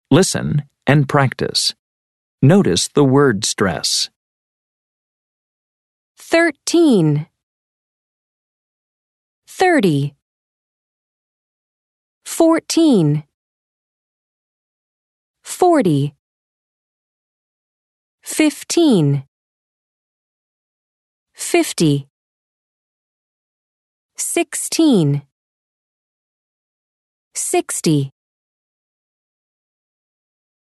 Listen and practice. Notice the word stress:
numbers_stress.mp3